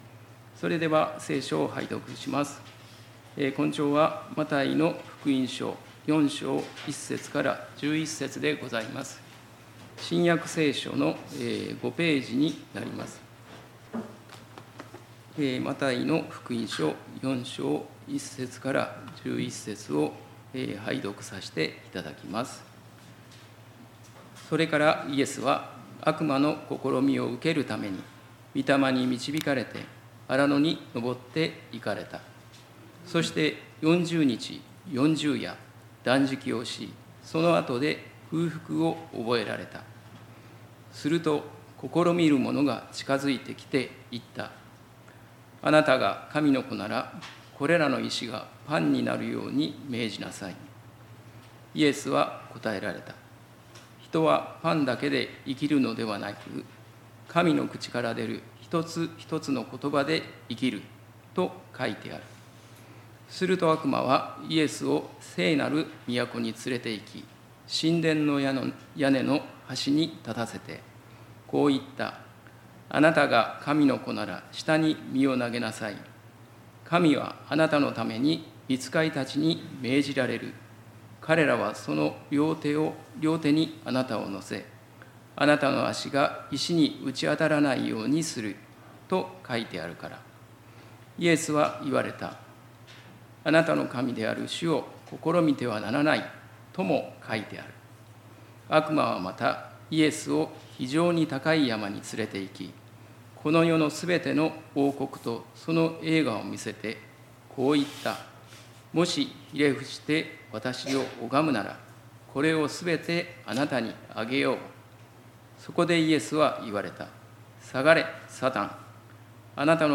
礼拝メッセージ「誘惑に勝つ」│日本イエス・キリスト教団 柏 原 教 会